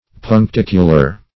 Search Result for " puncticular" : The Collaborative International Dictionary of English v.0.48: Puncticular \Punc*tic"u*lar\, a. Comprised in, or like, a point; exact.